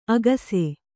♪ agase